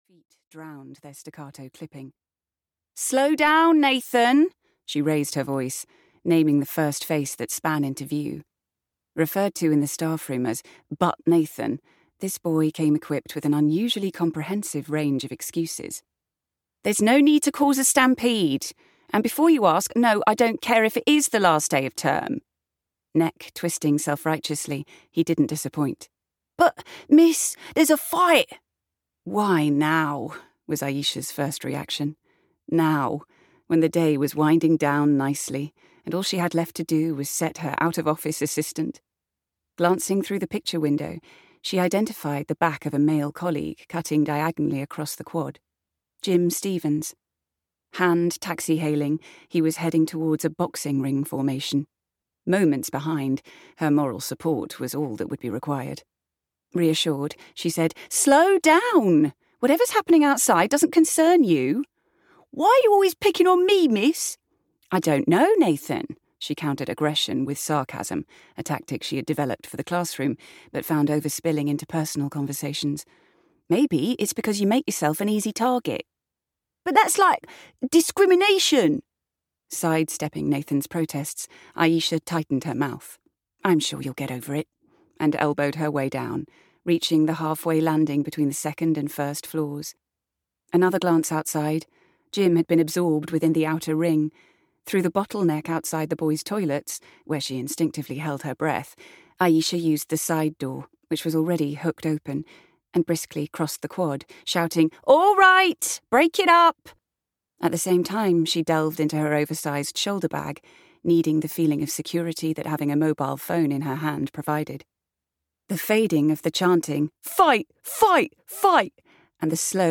A Funeral for an Owl (EN) audiokniha
Ukázka z knihy